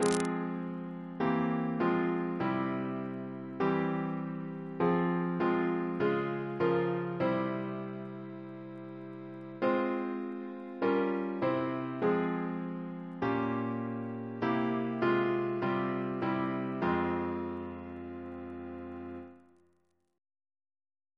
Double chant in F minor Composer: Edward John Hopkins (1818-1901), Organist of the Temple Church Reference psalters: ACB: 222; ACP: 45 119; H1982: S191; PP/SNCB: 153